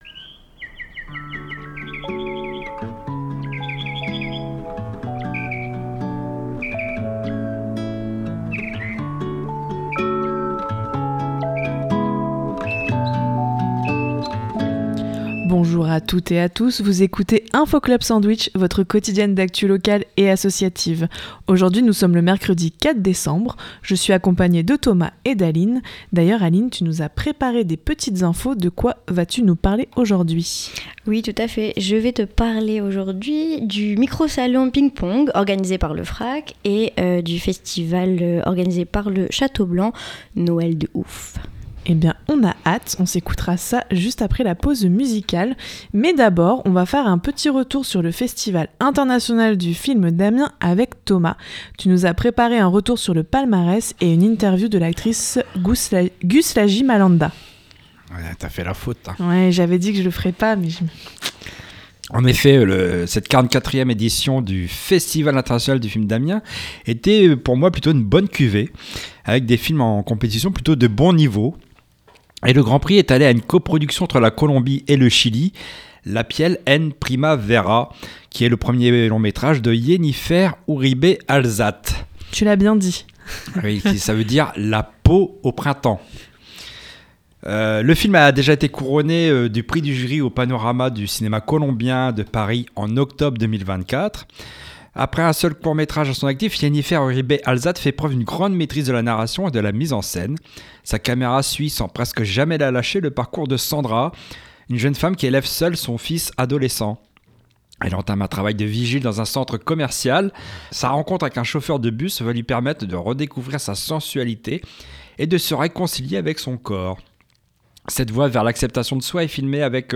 Elle était présente au Festival International du Film d’Amiens.